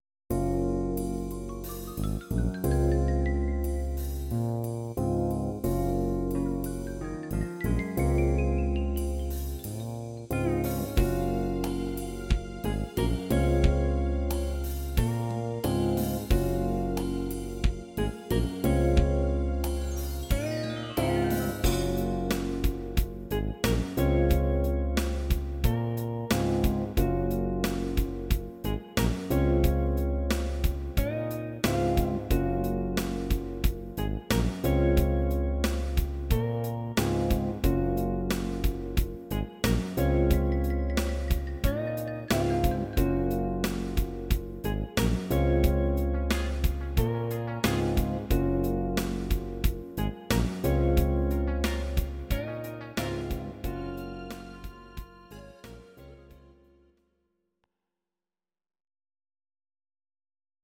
Audio Recordings based on Midi-files
Pop, 1970s